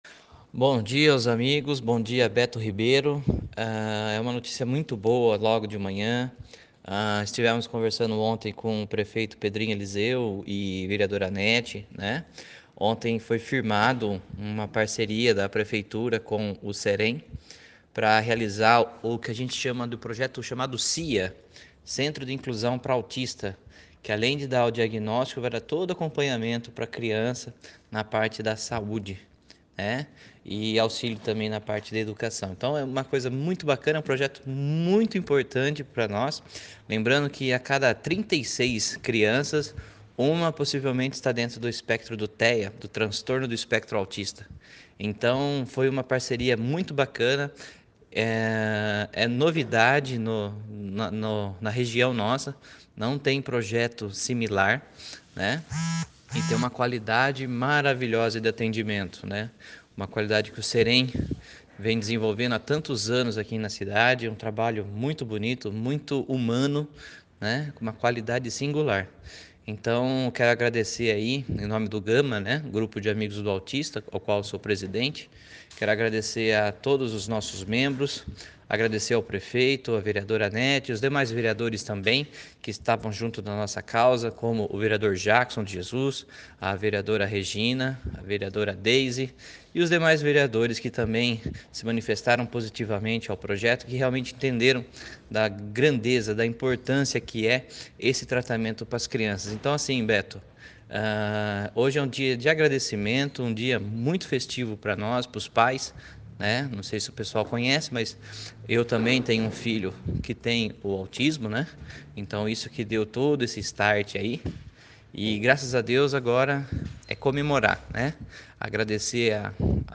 Nossa reportagem falou por telefone agora pela manhã com